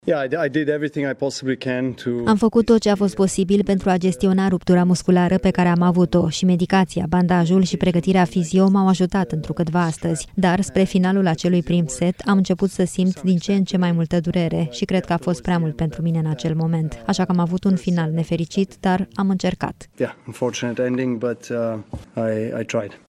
Într-o conferință de presă, Novak Djokovic a explicat că a resimțit dureri foarte mari din cauza unei rupturi musculare cu care se confruntase dinaintea meciului.